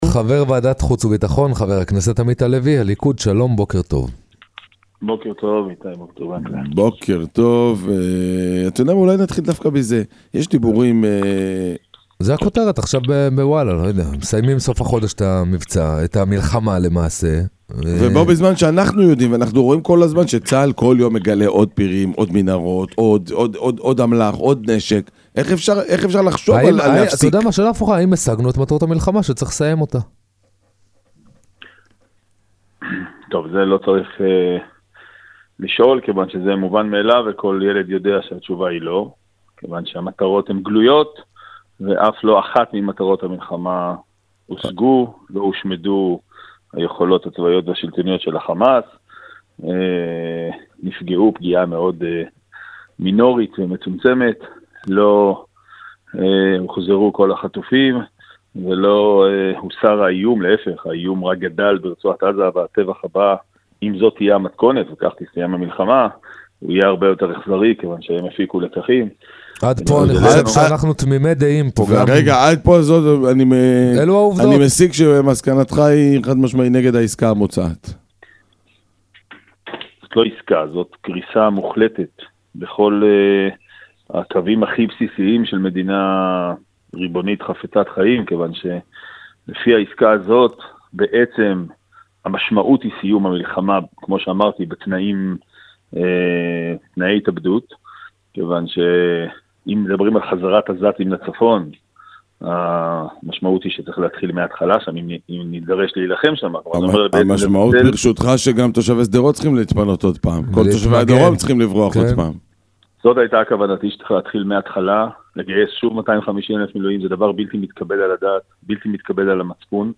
בראיון במהדורת סופ"ש